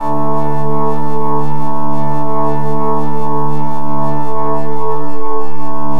SAWED     -L.wav